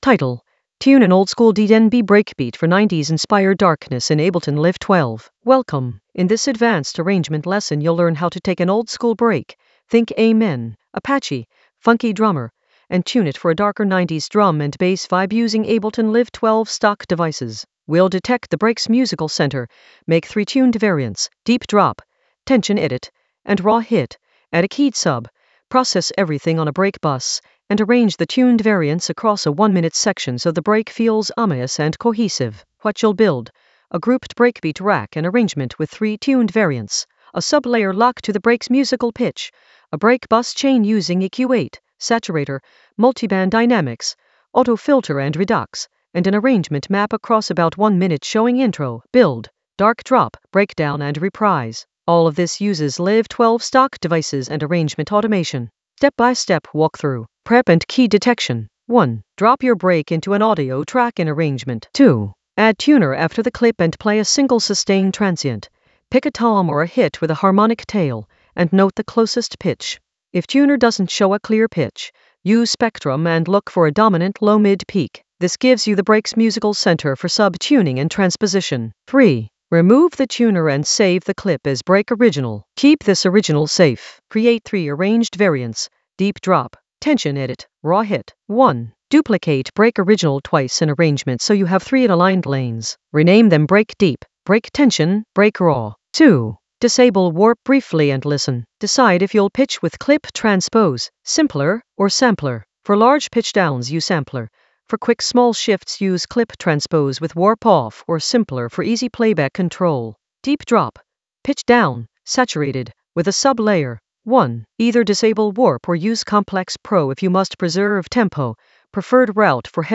Narrated lesson audio
The voice track includes the tutorial plus extra teacher commentary.
An AI-generated advanced Ableton lesson focused on Tune an oldskool DnB breakbeat for 90s-inspired darkness in Ableton Live 12 in the Arrangement area of drum and bass production.